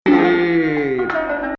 Rast 1